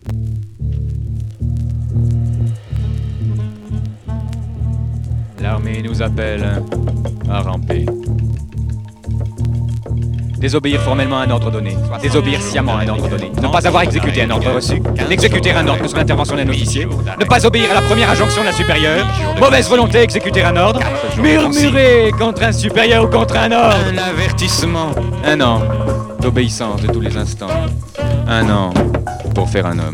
Progressif expérimental contestataire